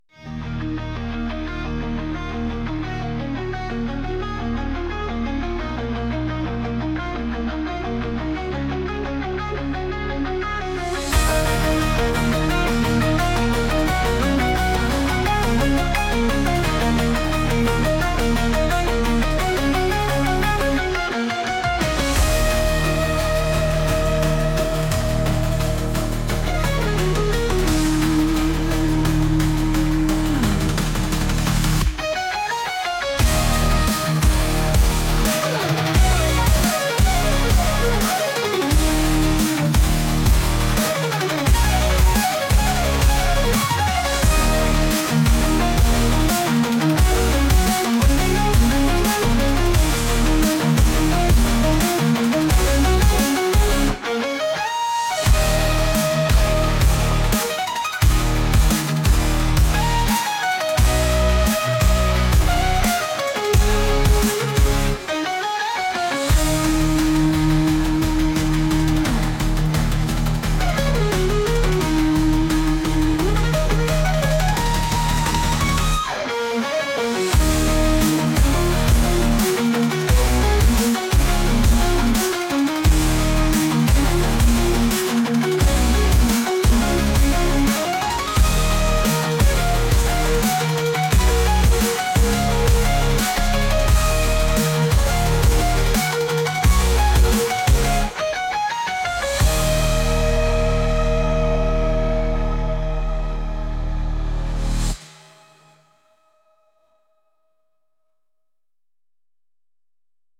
ゲームの中ボス戦のようなエレキギター曲です。